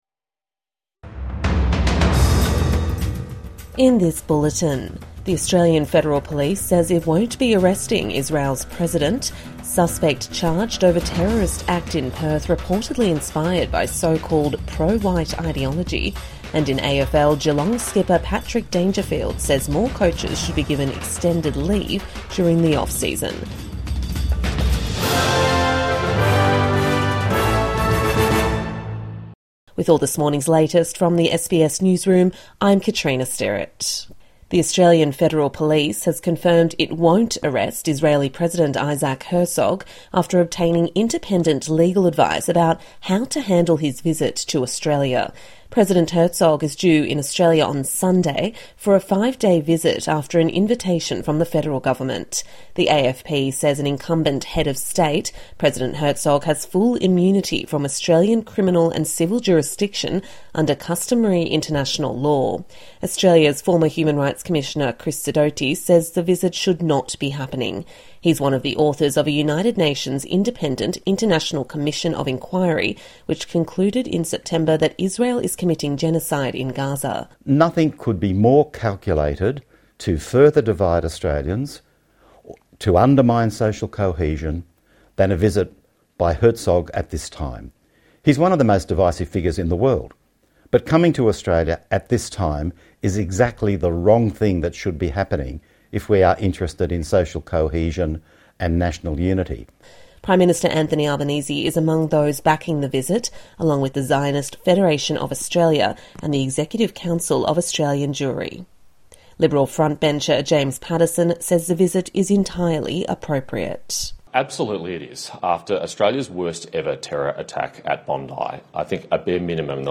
President Herzog immune from arrest in Australia | Morning News Bulletin 6 February 2026